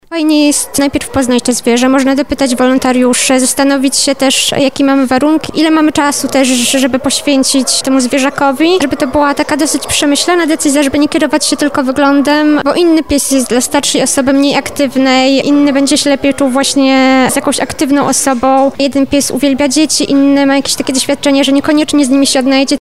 Z okazji tych świąt wybraliśmy się do Schroniska dla Bezdomnych Zwierząt w Lublinie.